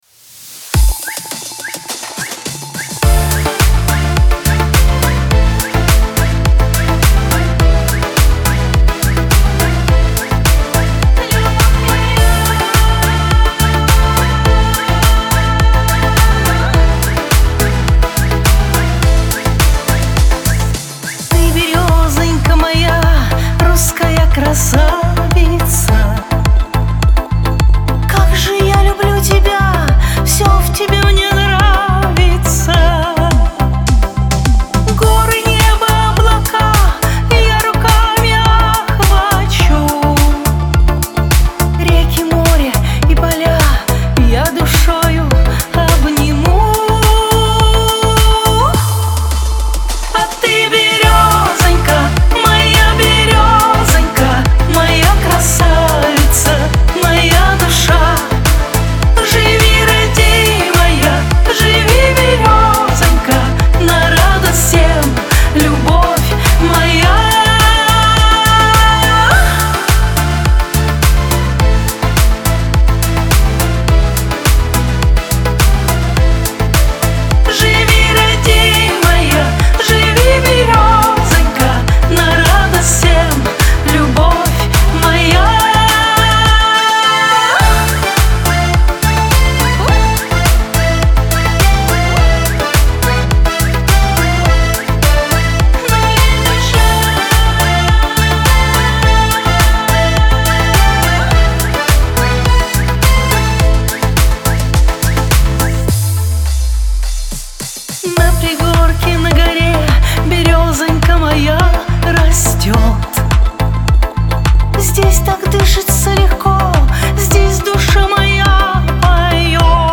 pop
Лирика